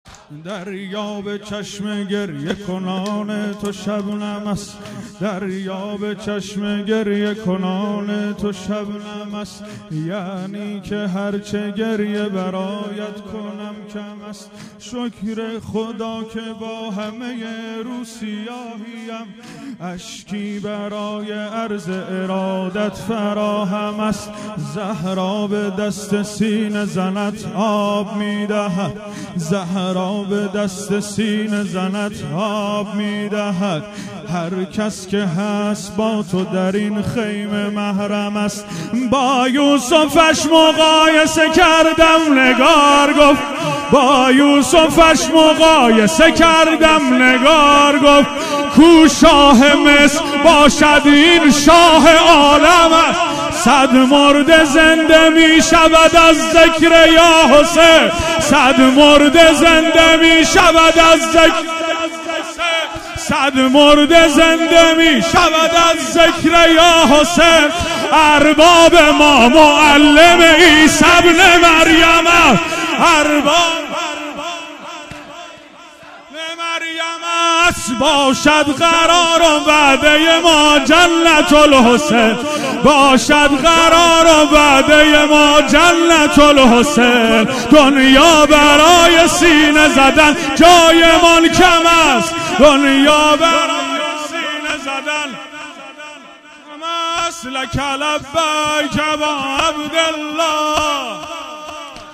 مداحی واحد دریا به چشم گریه کنان تو شبنم است